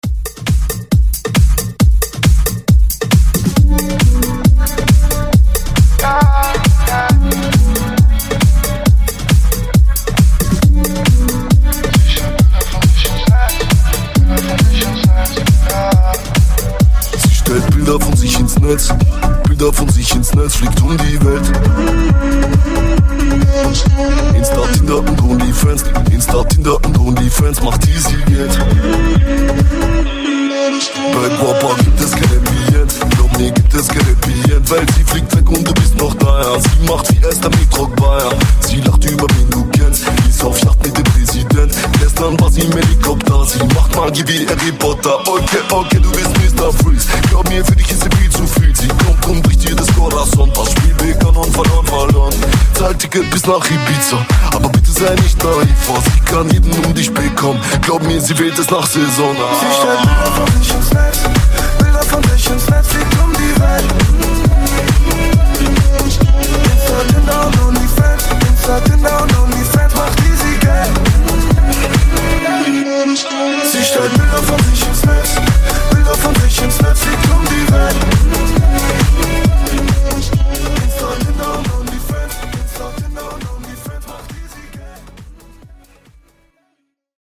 BPM: 136 Time